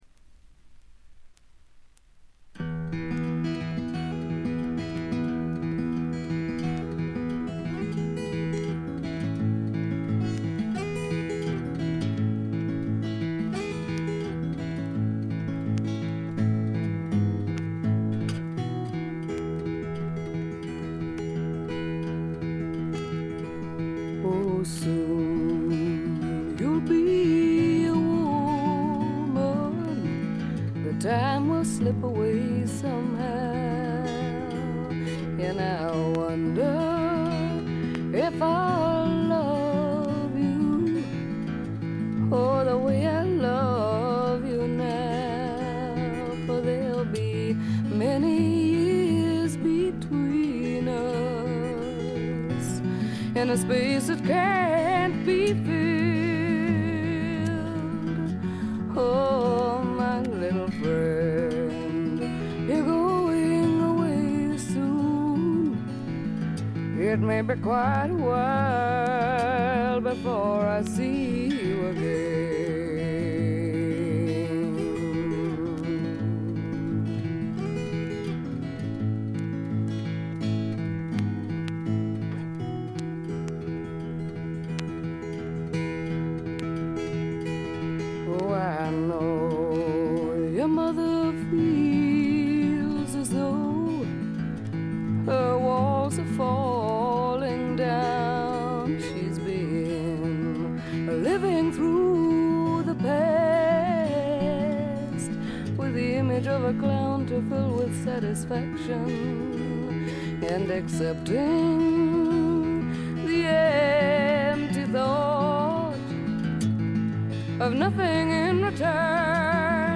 試聴曲は現品からの取り込み音源です。
Recorded at Nova Sound Recording Studios, London